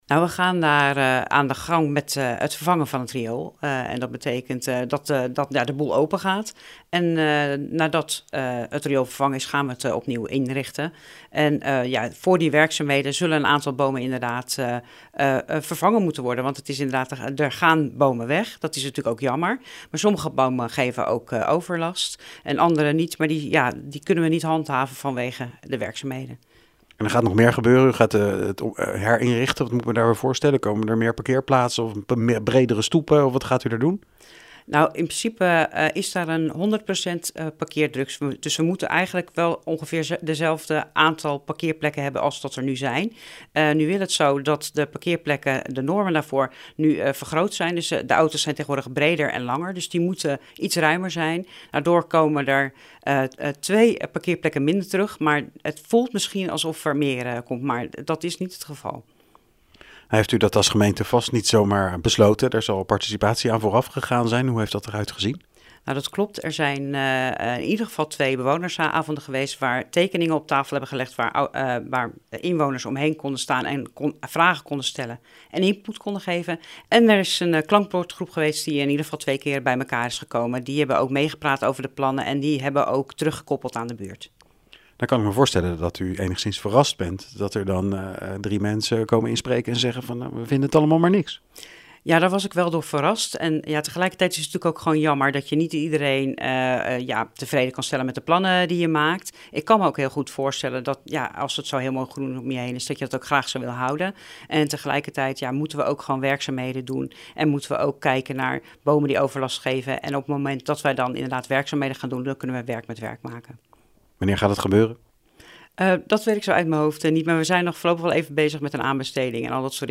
in gesprek met wethouder Angelique Beekhuizen over de herinrichting van de Vogelweide.